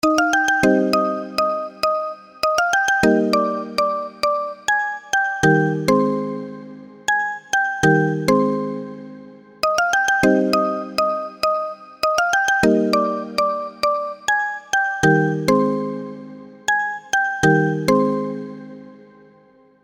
آهنگ زنگ خور موبایل غمگین بی کلام